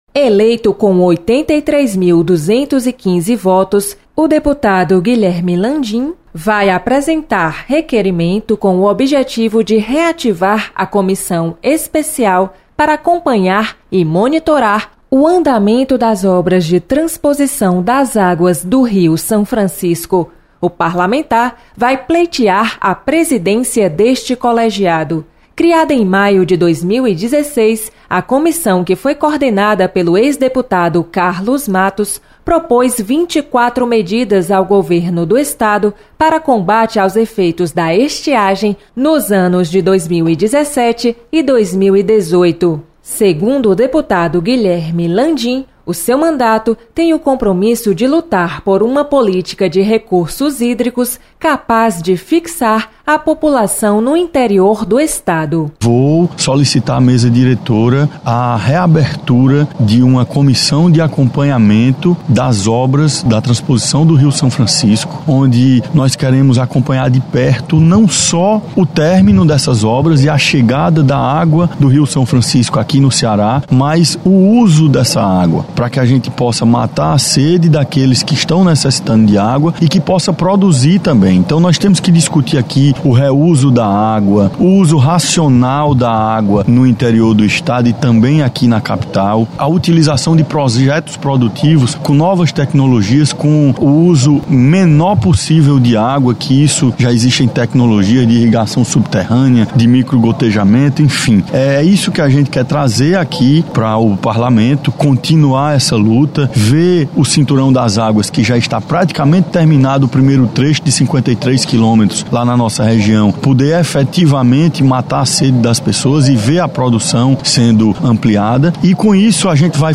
Deputado Guilherme Landim quer reativar Comissão Especial  que monitora obras da Transposição do Rio São Francisco. Repórter